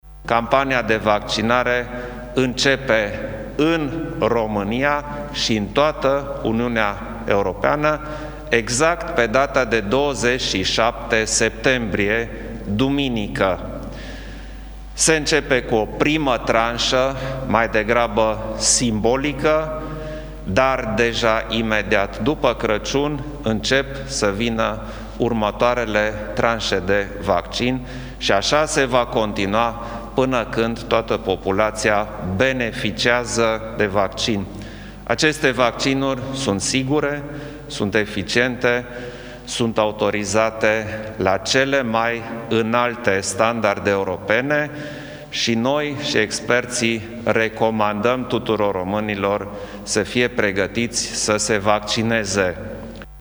Vaccinurile sunt sigure, mai transmite președintele Klaus Iohannis, la finalul ședinței de astăzi de la Palatul Cotroceni: